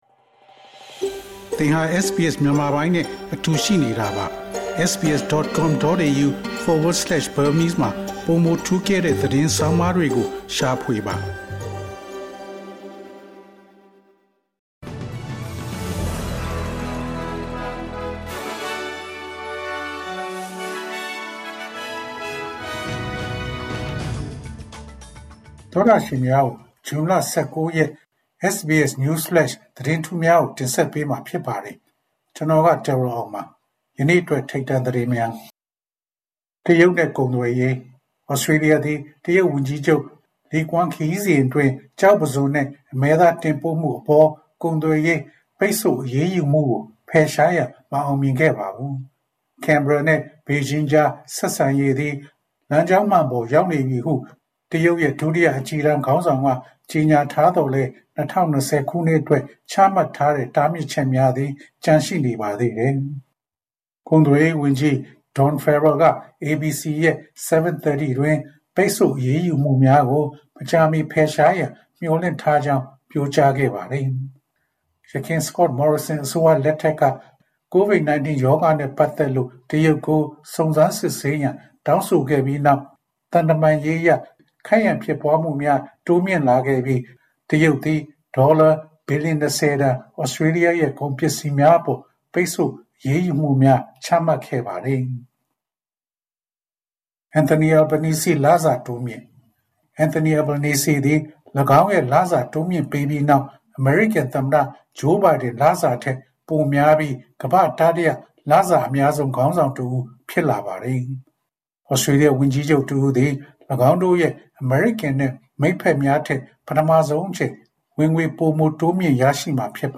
ALC: SBS မြန်မာ ဇွန်လ ၁၉ ရက် News Flash သတင်းများ။